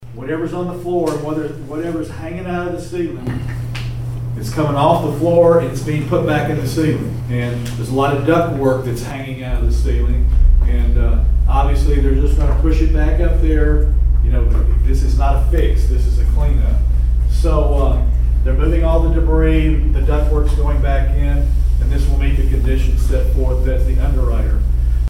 Mayor Belote explains that this is not a fix, it’s a cleanup.